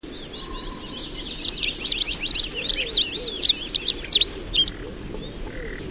Pokrzywnica - Prunella modularis